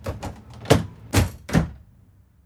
ambdoorclose.wav